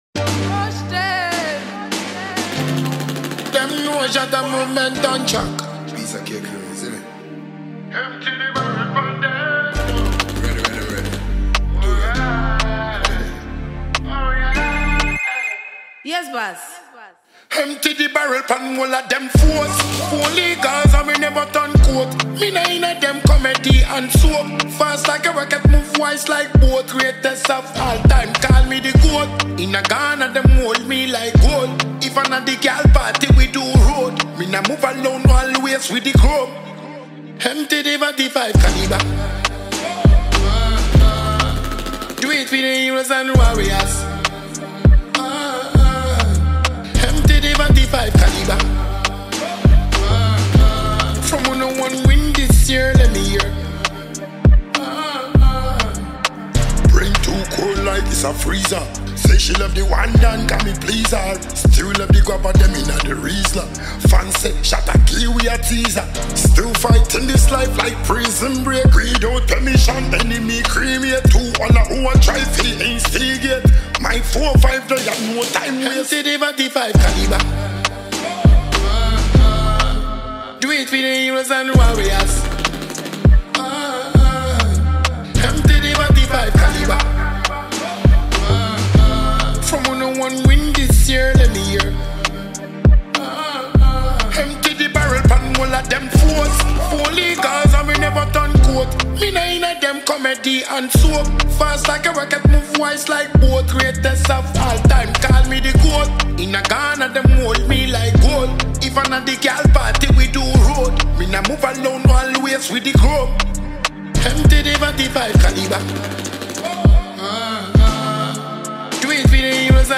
Ghanaian dancehall musician and songwriter